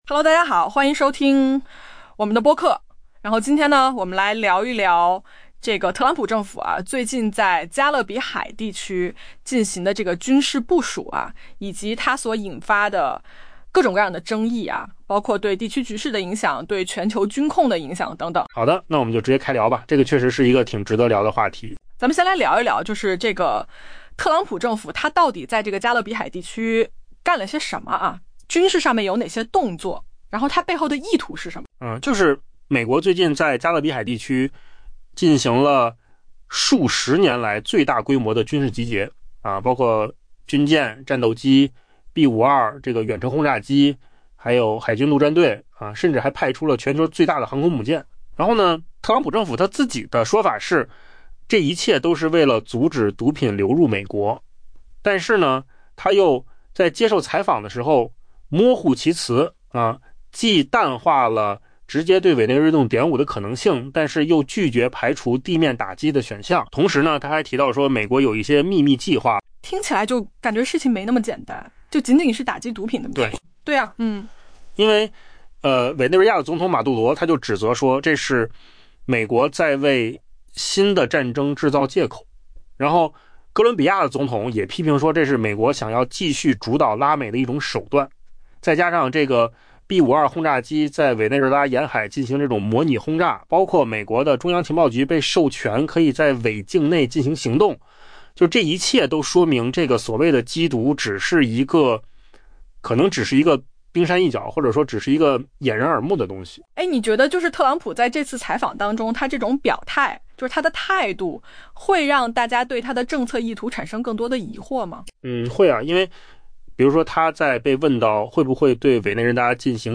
AI 播客：换个方式听新闻 下载 mp3 音频由扣子空间生成 特朗普淡化了美国与委内瑞拉爆发战争的可能性，但暗示马杜罗的总统任期已进入倒计时。